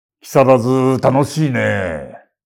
アクアコイン決済音にご協力いただきました
電子地域通貨「アクアコイン」の運用開始5周年を記念し、アクアコイン決済音5周年記念特別バーションにご協力いただきました。
特別決済音「木更津、楽しいねぇ」 (音声ファイル: 66.5KB)